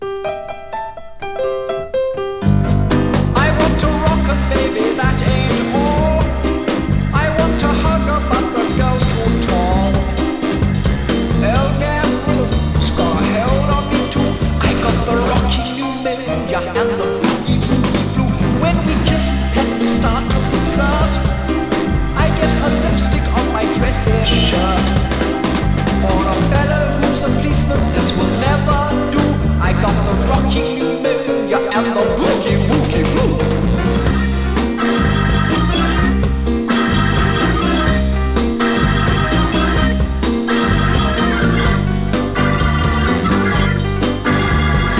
大所帯なバンドサウンドに味のあるヴォーカルと鍵盤炸裂。
Rock & Roll, Rock, Pop　USA　12inchレコード　33rpm　Stereo